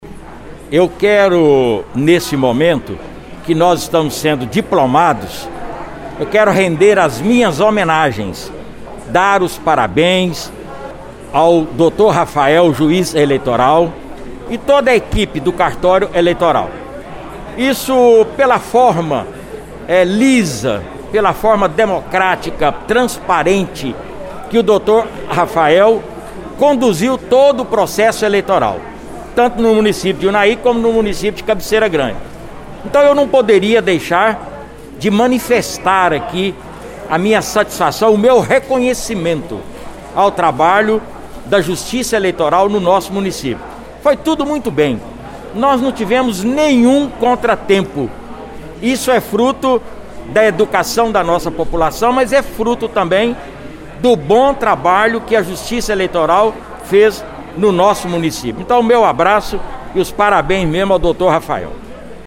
A reportagem da Rádio Veredas ouviu alguns dos personagens deste evento que consolidou a democracia nos dois municípios.